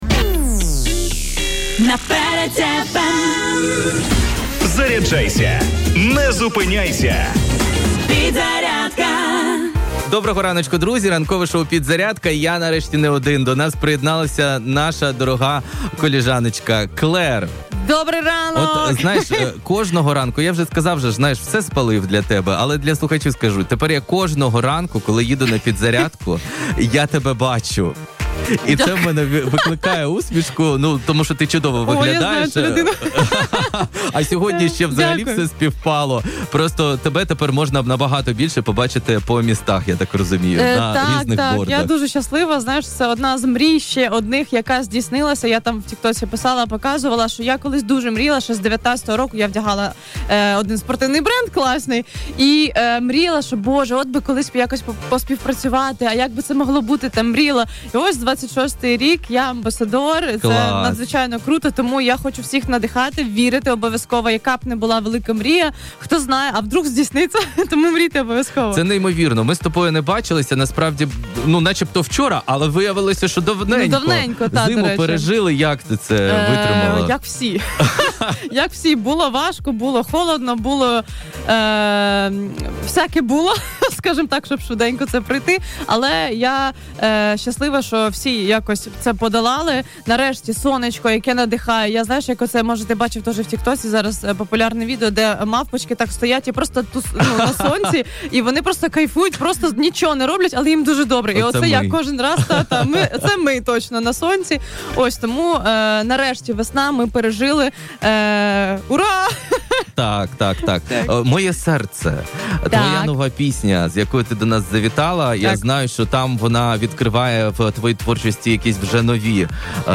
У ранковому шоу «Підзарядка» на радіо Перець ФМ відбулася музична прем’єра.